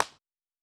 Ball Hit Distant.wav